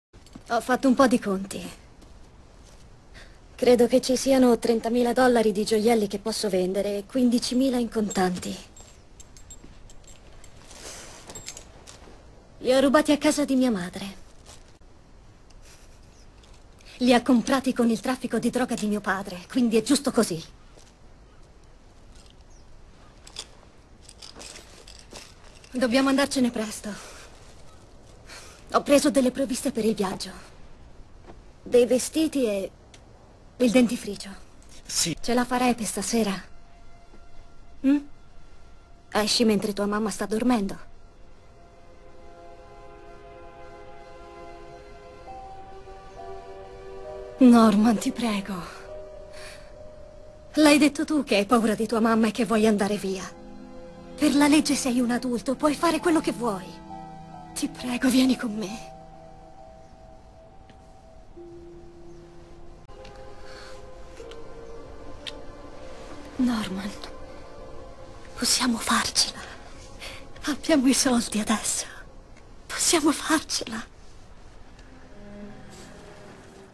nel telefilm "Bates Motel", in cui doppia Nicola Peltz.